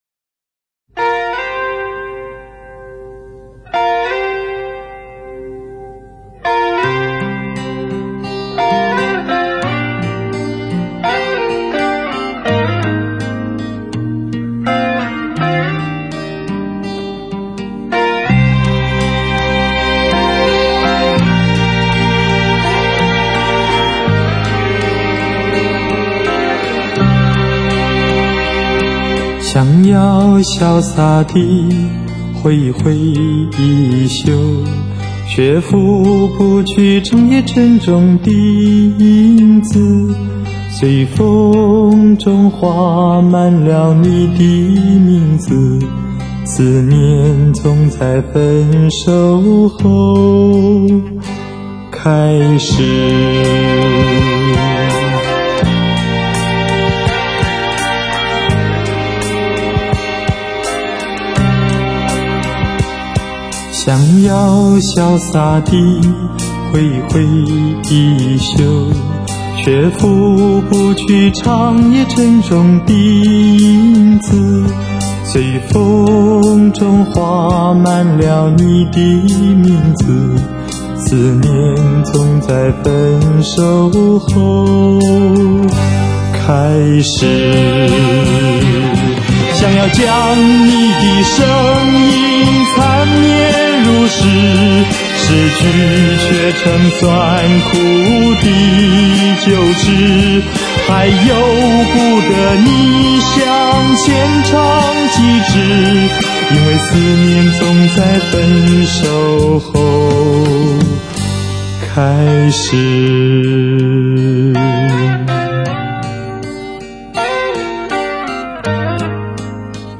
專輯類別：國語流行、絕版重現